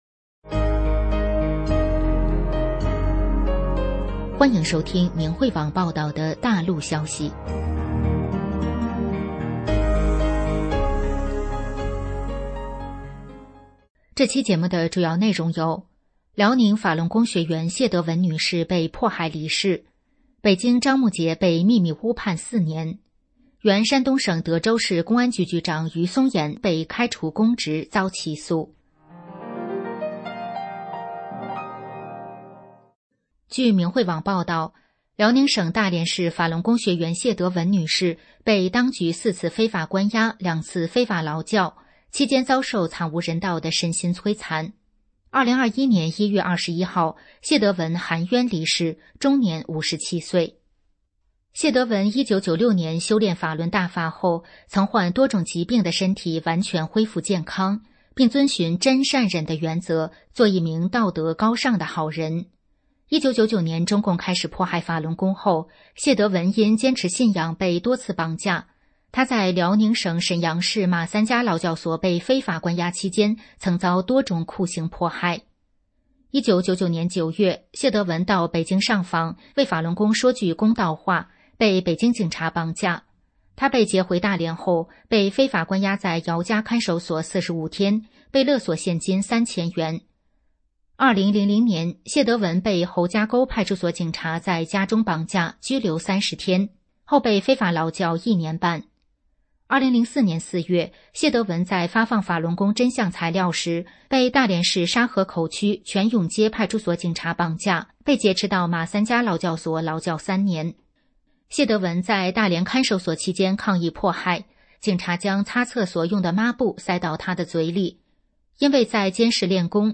大陆消息